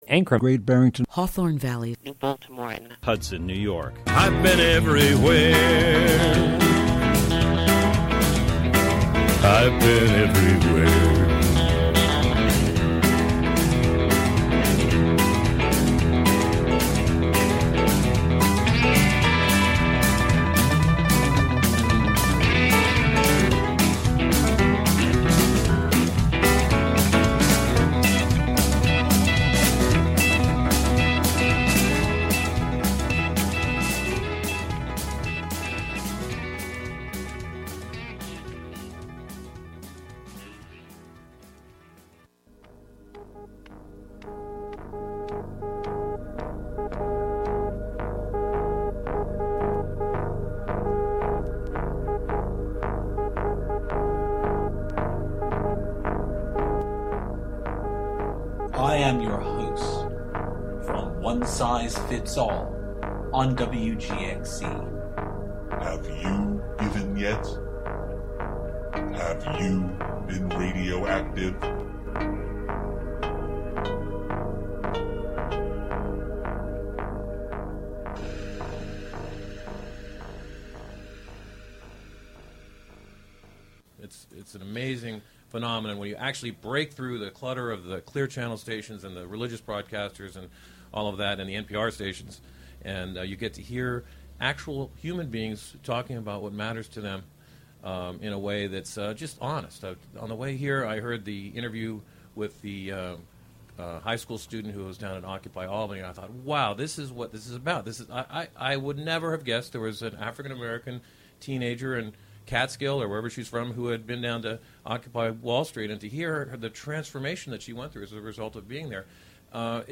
broadcasting live from the Historic Catskill Point